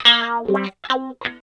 74 GTR 1  -R.wav